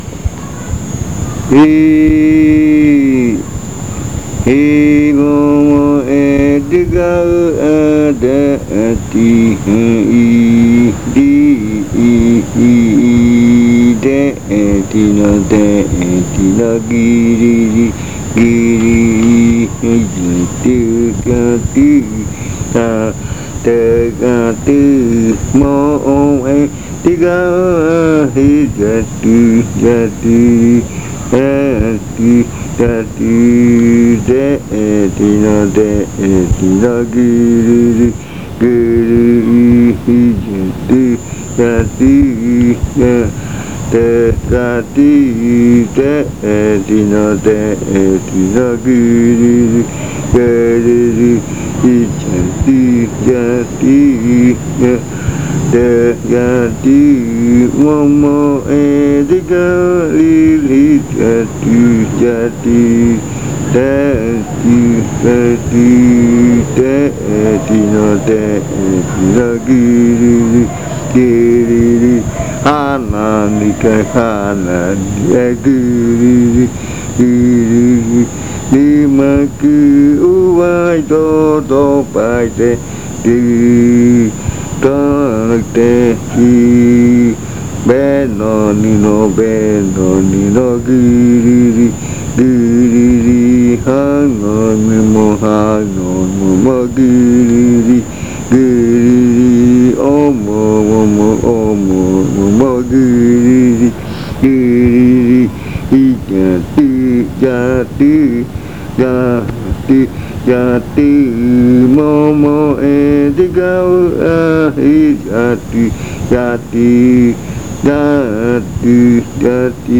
Leticia, Amazonas
Canto que se canta en la madrugada, como despedida. Esta canción la cantan algunos cantores murui, pero este canto ritual es de la etnia muinane y algunos murui que entienden la lengua muinane cantan esta canción a su manera de entender.
A chant that is sung in the early morning, as a farewell. This chant is sung by some Murui singers, but this ritual chant is from the Muinane ethnic group and some Murui who understand the Muinane language sing this chant in their own way.